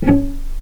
healing-soundscapes/Sound Banks/HSS_OP_Pack/Strings/cello/pizz/vc_pz-C#4-pp.AIF at 61d9fc336c23f962a4879a825ef13e8dd23a4d25
vc_pz-C#4-pp.AIF